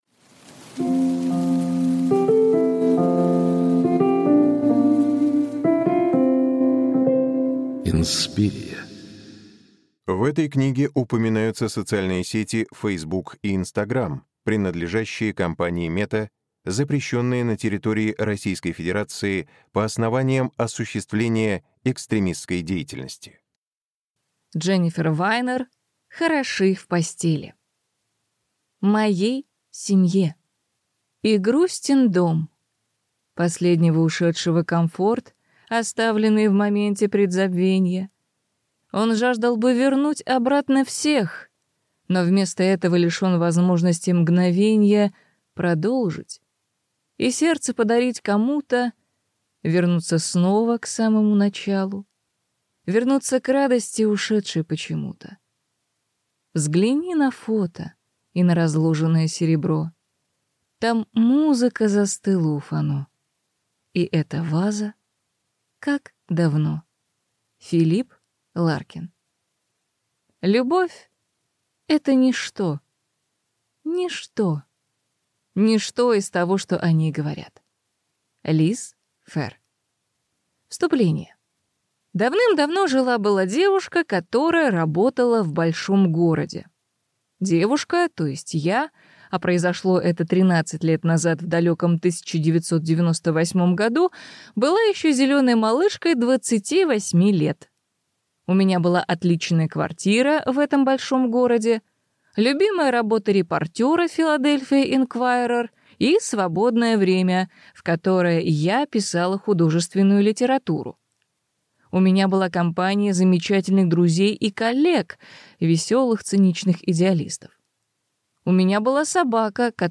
Аудиокнига Хороши в постели | Библиотека аудиокниг